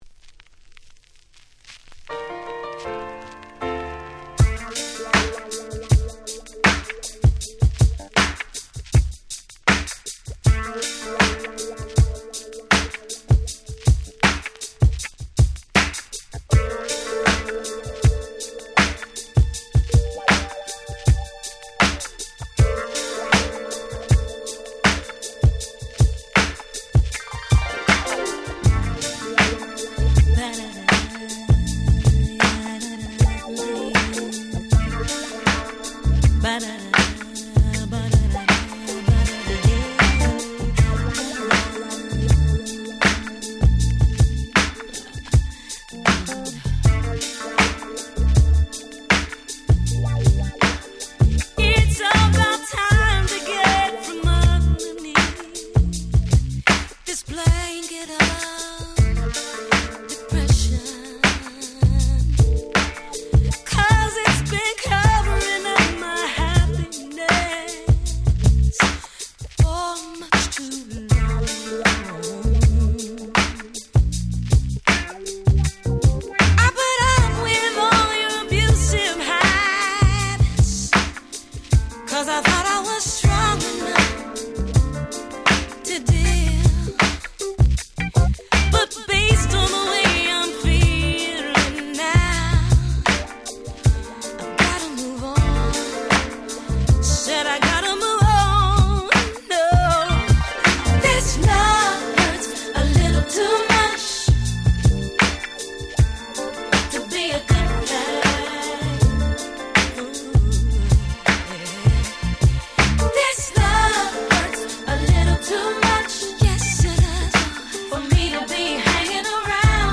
98' マイナーSoul / R&B !!
コアなR&Bファンを唸らせるこのGrooveとVocal、最高です。
全くキャッチーではございませんが、聴けば聴くほどに味の出てくる素敵な1曲です。